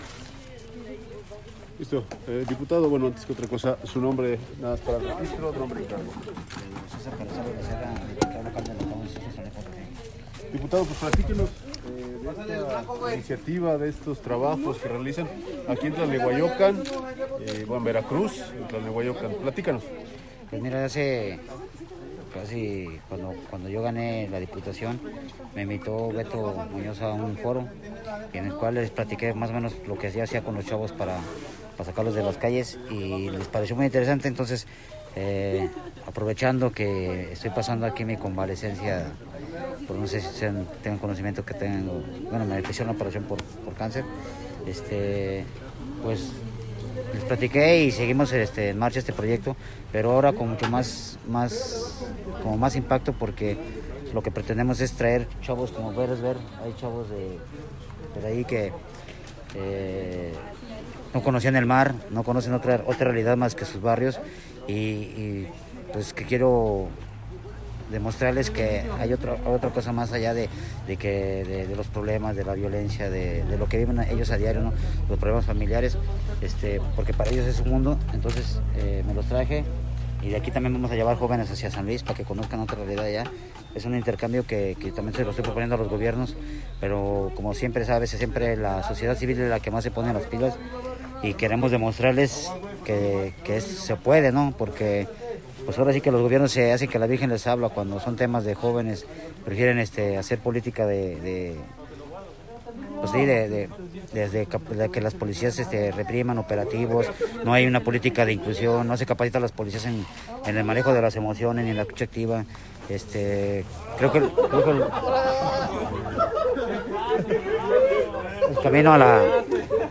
Lo anterior lo dio a conocer durante su recorrido en la localidad de Rancho Viejo, de San Andrés Tlalnelhuayocan, municipio conurbado con la ciudad de Xalapa.